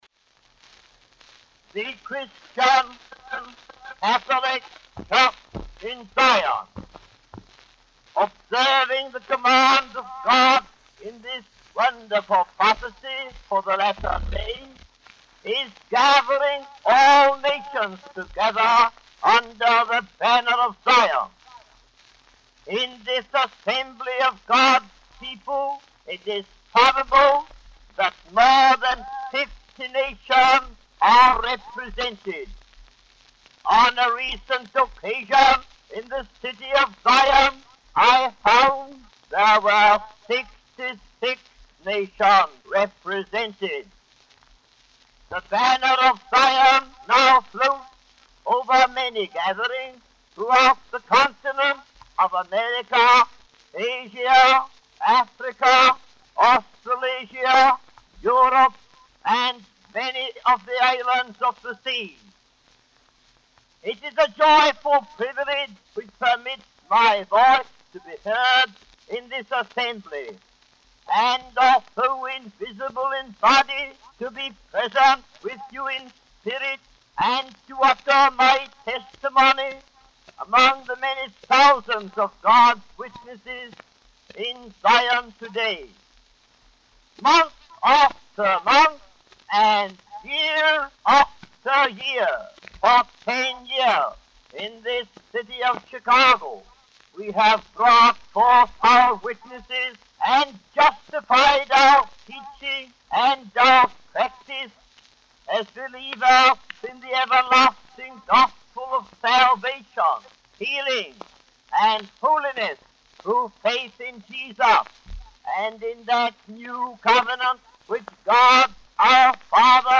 The sermon emphasizes the gathering of nations under the banner of Zion, representing a fulfillment of the prophecy of the latter days and the spread of the gospel of salvation, healing, and holiness.